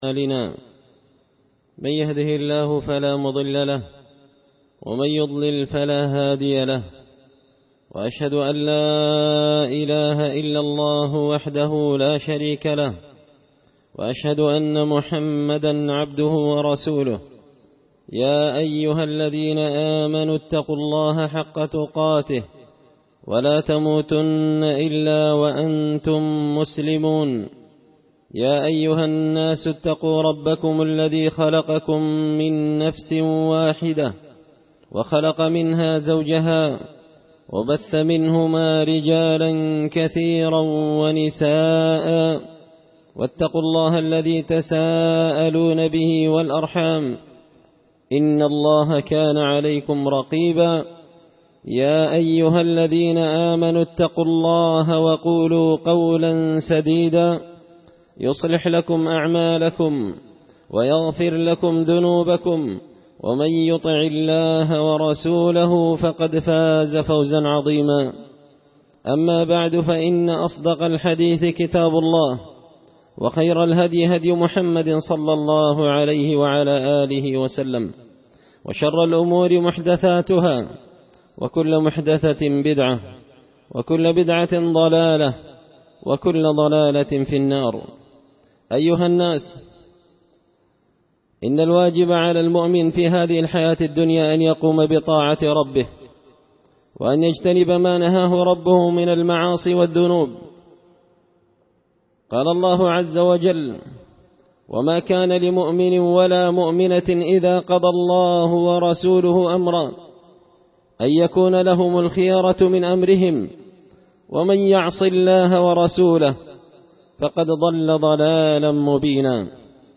خطبة جمعة بعنوان – وابك على خطيئتك
دار الحديث بمسجد الفرقان ـ قشن ـ المهرة ـ اليمن